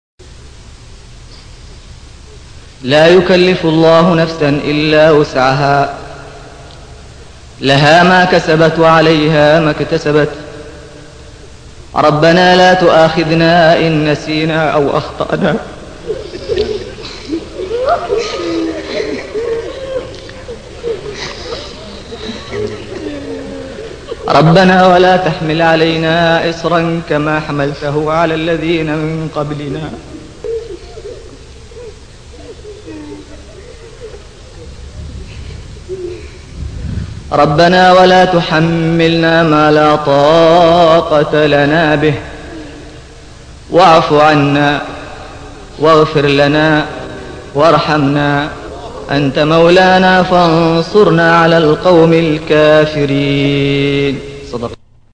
صوت جميل على ترتيل خاشع ومنيب